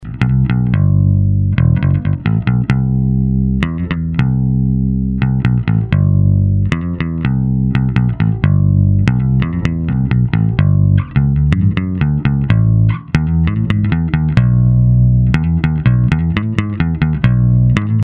Nakonec jsem po mnoha diskusích na zahraničních fórech namotal sadu Rotosound Swing Bass a ty tomu sedí.
První ukázka je hraná prstem, jen kobylkový snímač, ta druhá trsátko, kobylkový snímač, a třetí trsátkem, oba snímače .